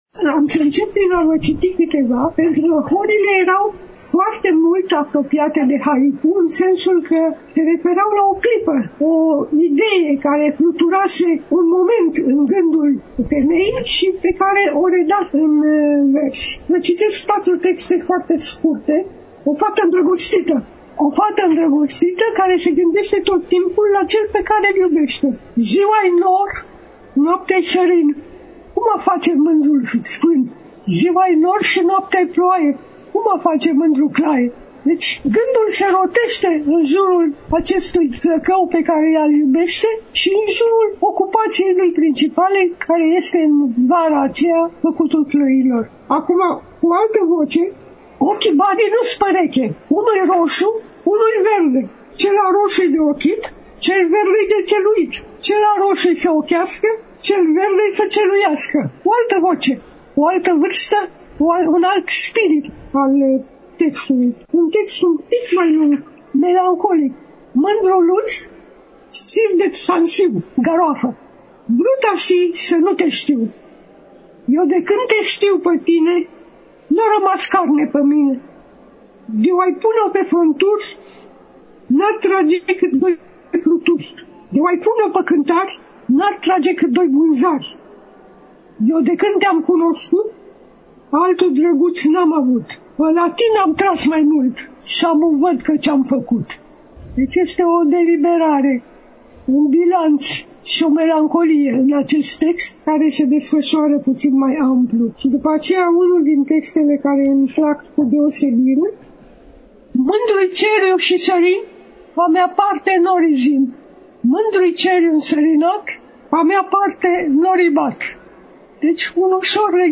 Astăzi, relatăm de la Târgul de Carte Gaudeamus Radio România, ediția a XXIX-a, 7 – 11 decembrie 2022, Pavilionul B2 al Complexului Expoziţional Romexpo, București.